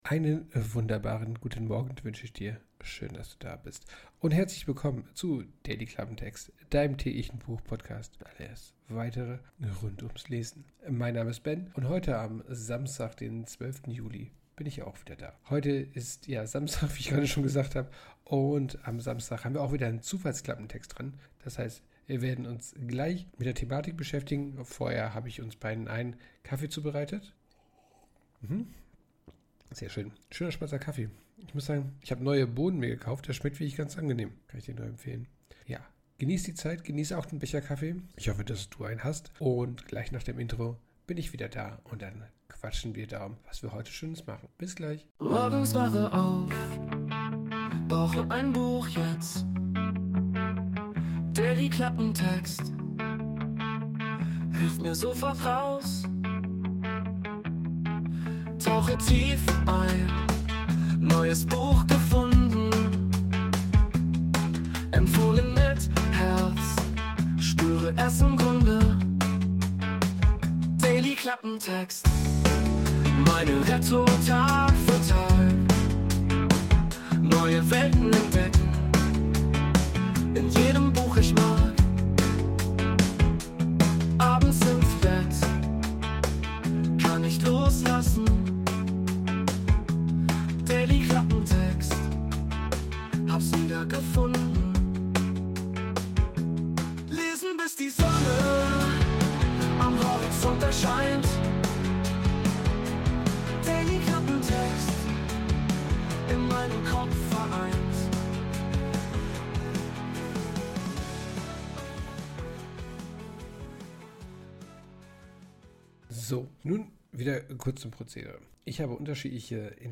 Intromusik: Wurde mit der KI Suno erstellt.